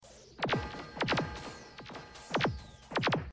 The Black Hole FX - Lasers
The_Black_Hole_FX_-_Lasers.mp3